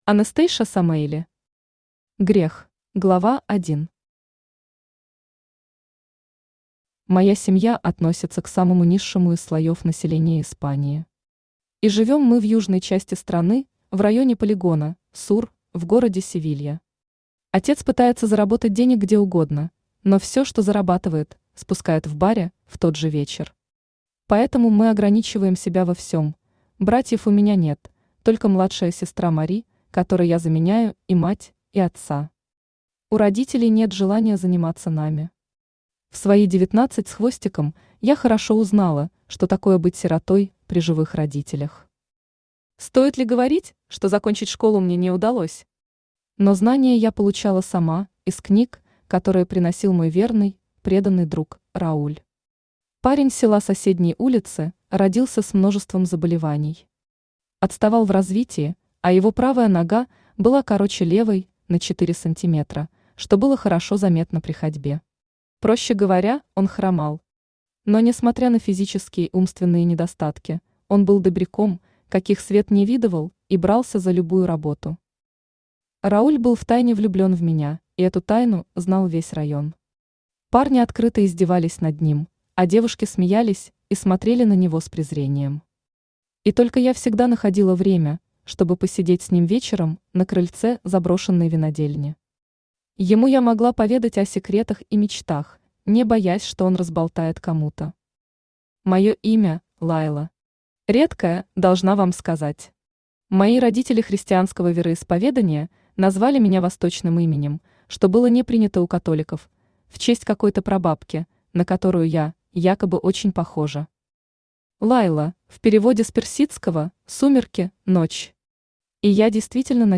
Aудиокнига Грех Автор Anastasia Avi Samaeli Читает аудиокнигу Авточтец ЛитРес.